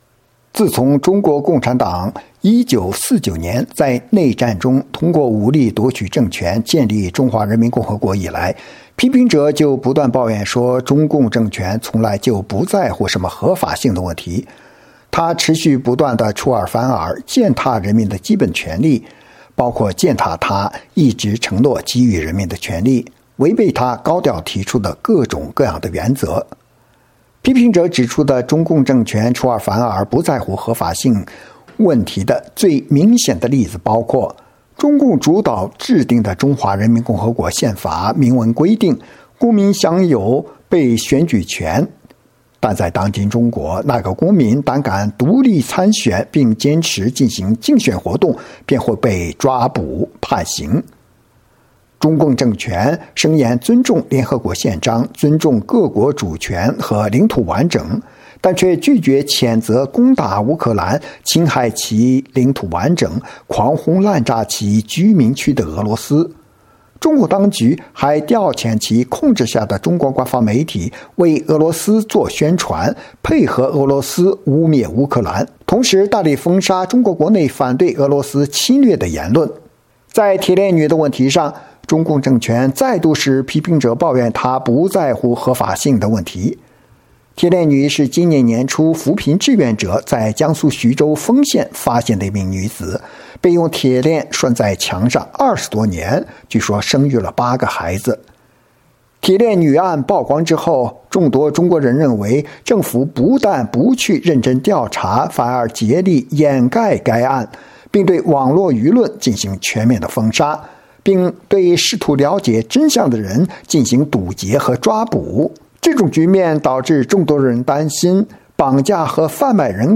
专访黎安友(2): 谈中国共产党政权合法性问题